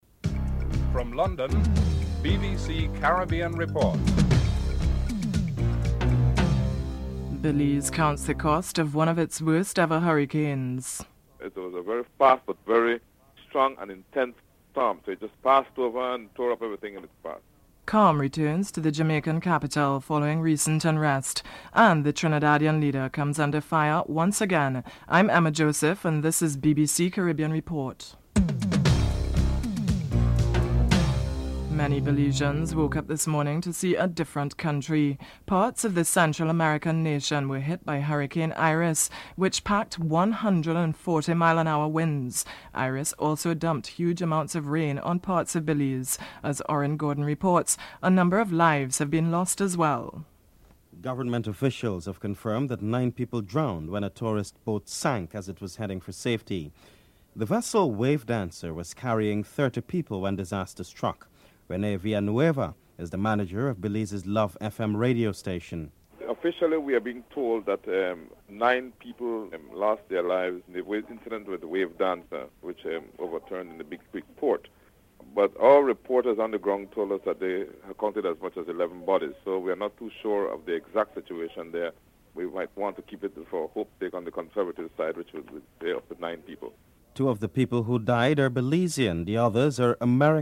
1. Headlines (00:00-00:30)
4. Caribbean Tourism Ministers and officials met today in the Bahamas to discuss the decline in tourism since last month's attacks in America. Incoming Prime Minister of Aruba Nelson Oduben is interviewed (11:46-13:13)